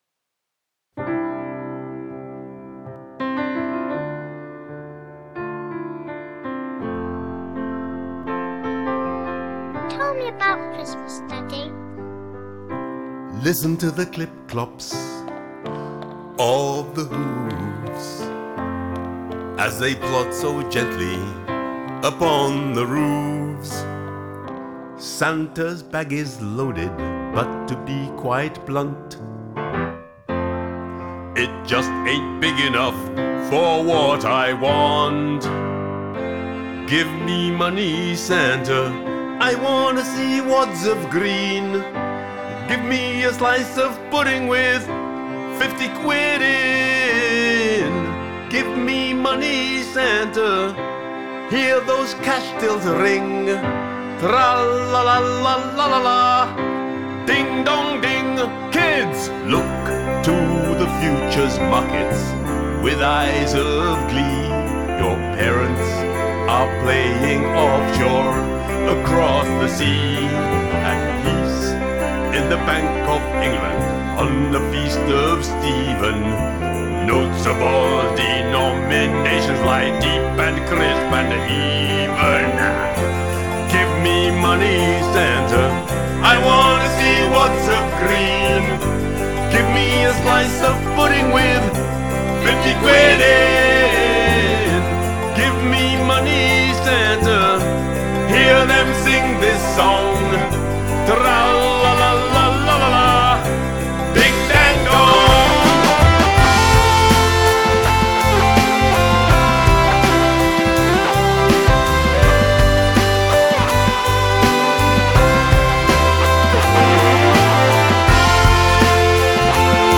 Vocals and piano
Backing vocals and chimta
Backing vocals and violin
Guitar, bass, drums and percussion programming
Guitar
Bass
Percussion
Keyboards and piano
Cello
Trumpet
Trombone
Reindeer sound effects
Young child
Choir (Germany)
Choir (UK)